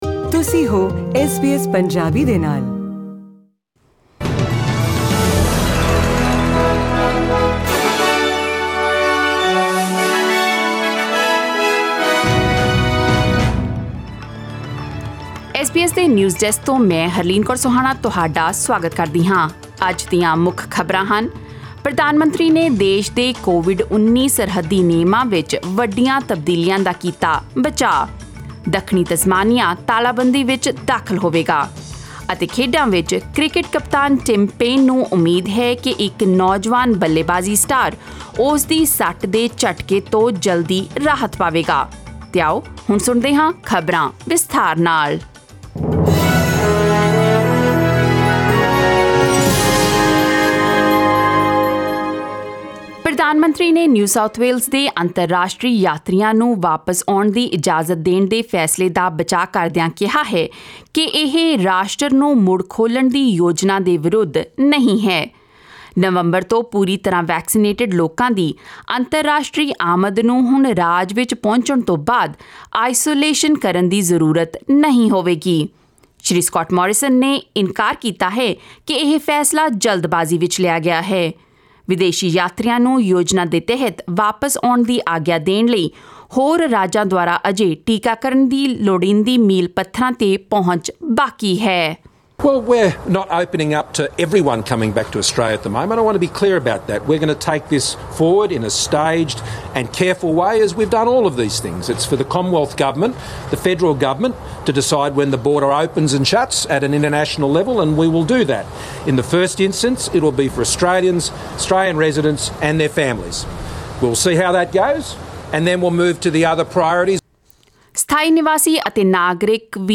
Listen to the latest news headlines in Australia from SBS Punjabi radio.